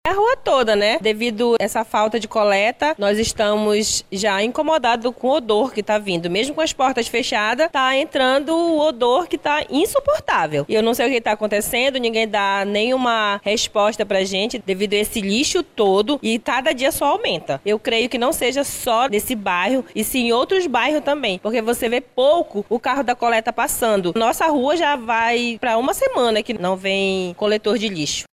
Moradora do Cidade de Deus